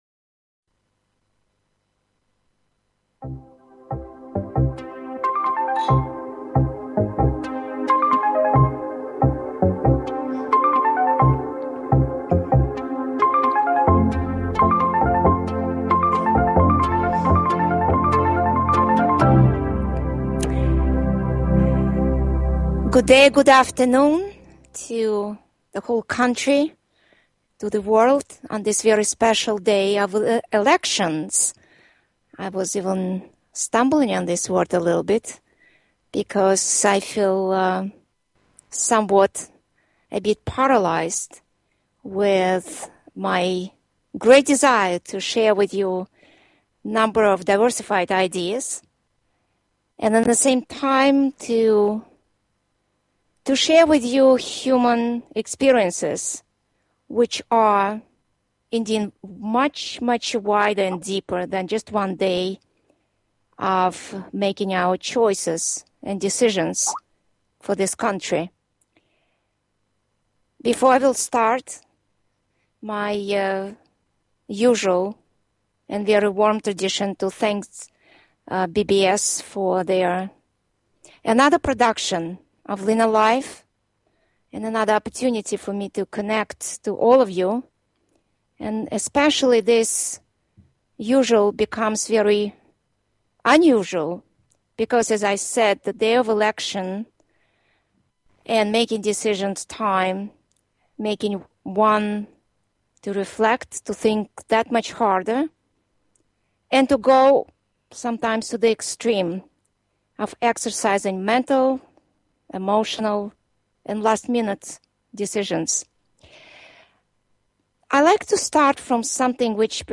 Piano Music Stimulating Conversations
Talk Show